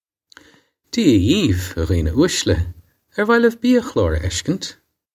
Pronunciation for how to say
Jee-ah yeev, a ghee-na oosh-la. Air vah liv bee-a-khlawr ah esh-kinch?